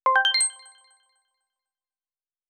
Coins (29).wav